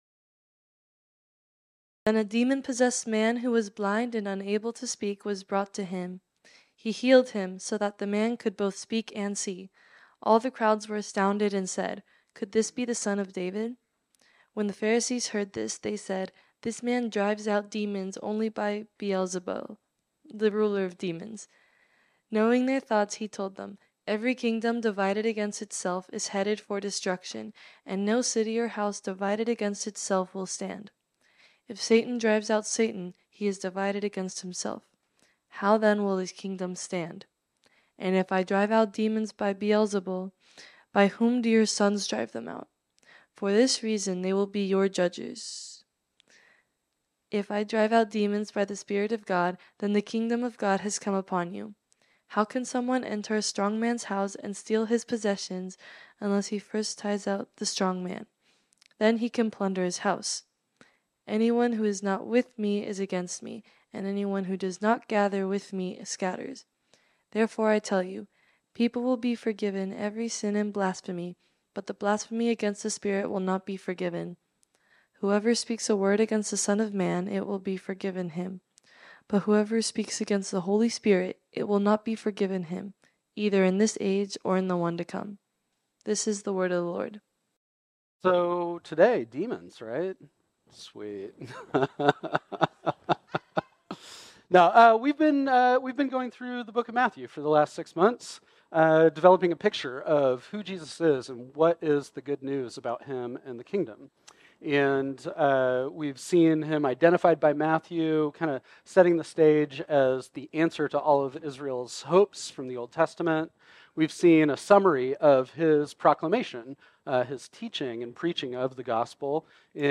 This sermon was originally preached on Sunday, June 2, 2024.